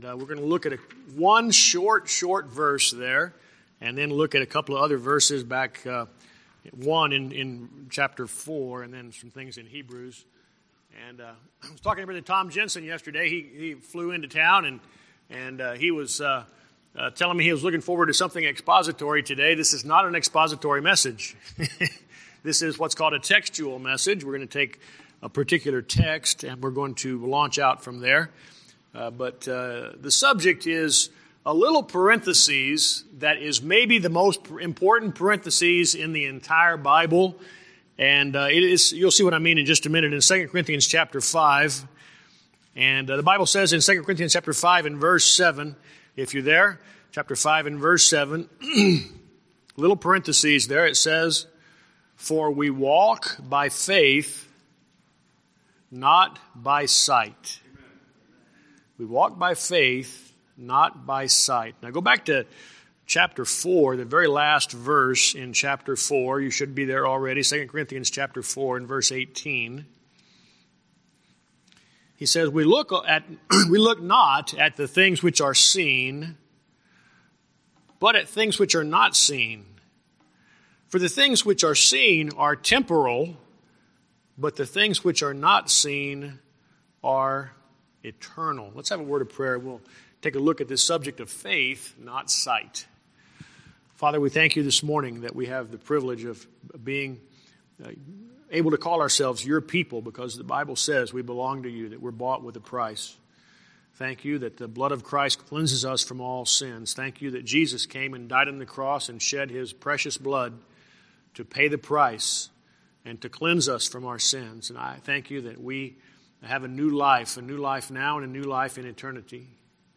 Sermons
Email Details Series: Guest Speaker Date